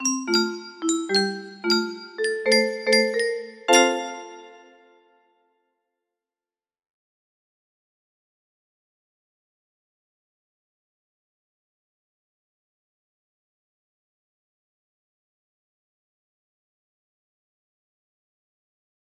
Okolo Hradce music box melody